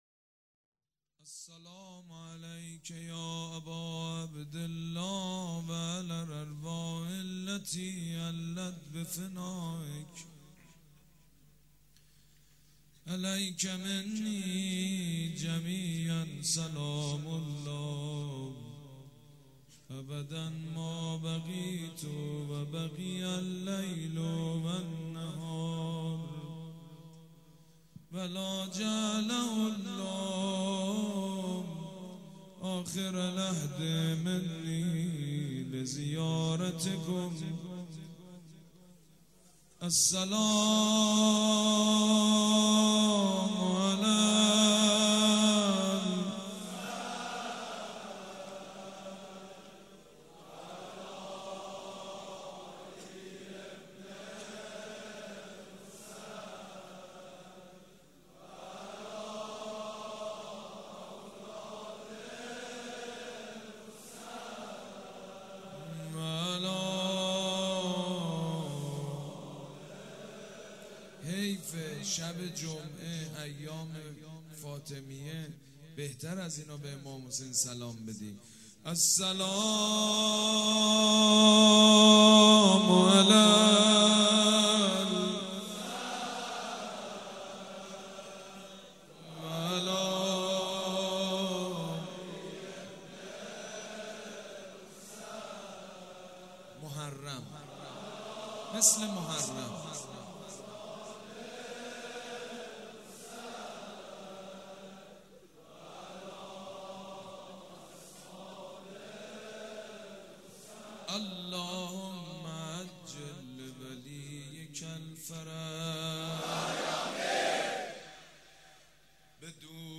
روضه حضرت سيدالشهدا(ع)
شب سوم فاطميه دوم١٣٩٤ هيئت ريحانة الحسين(س)
سبک اثــر روضه
مداح حاج سید مجید بنی فاطمه